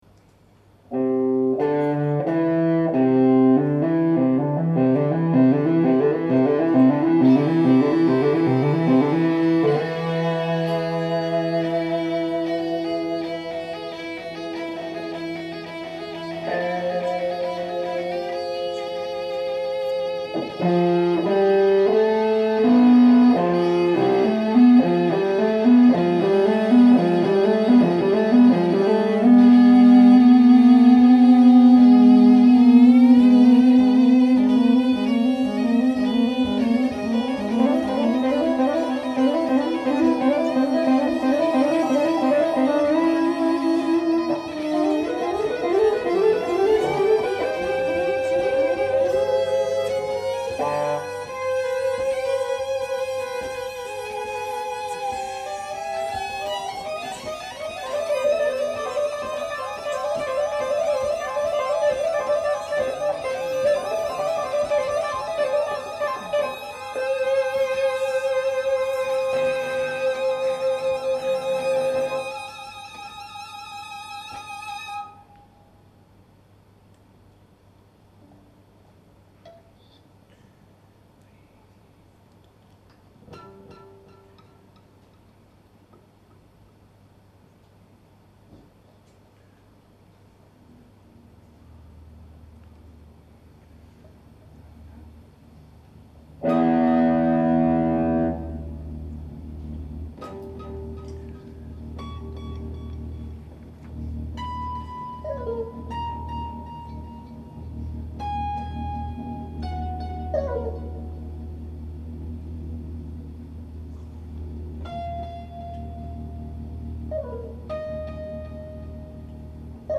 for electric guitar, electric violin and electric cello